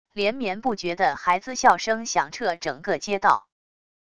连绵不绝的孩子笑声响彻整个街道wav音频